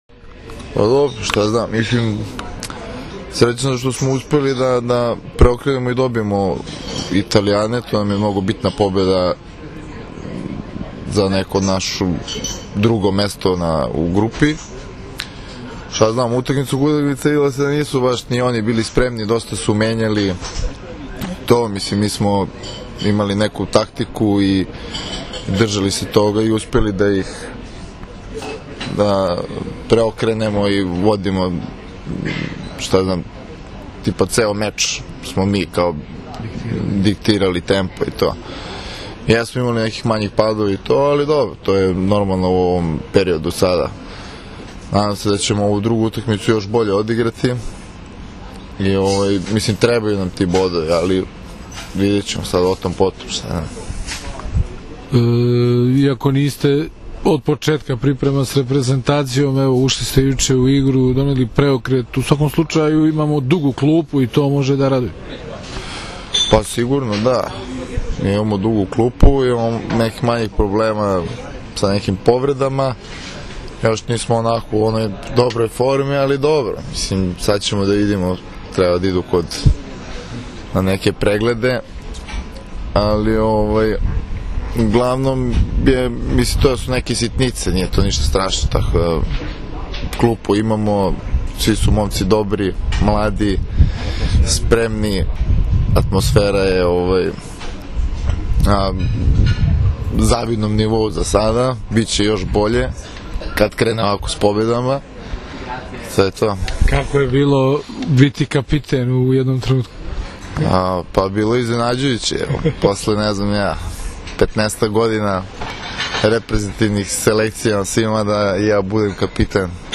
IZJAVA NIKOLE KOVAČEVIĆA